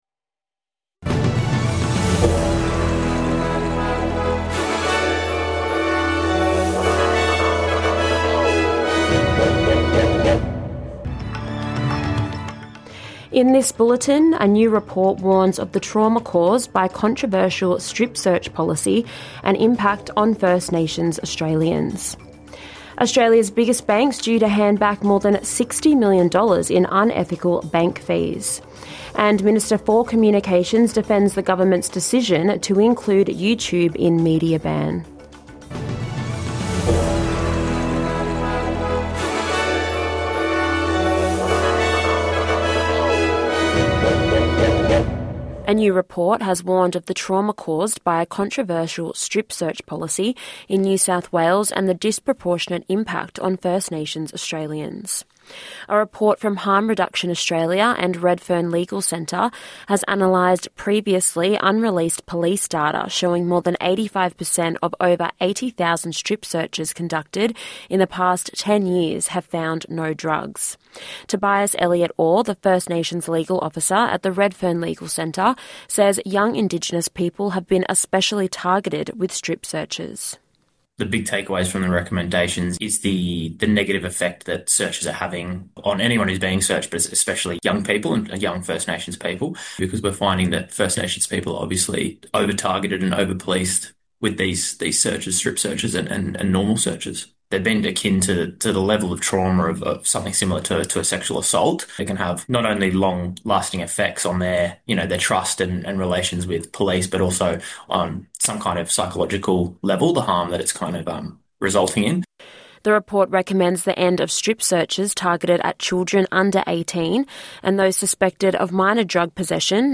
NITV Radio - News 30/7/2025